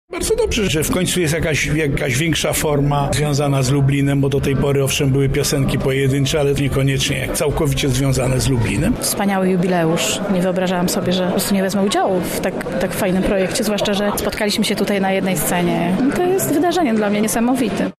W sobotę w sali operowej Centrum Spotkania Kultur widzowie wysłuchali kantaty skomponowanej specjalnie na okazję rocznicy przyznania praw miejskich.
Opowieść o powstaniu miasta wyśpiewali artyści lubelskiej estrady.
Oprawę muzyczną jazzrockowej kantaty przygotował muzyk i kompozytor Tomasz Momot.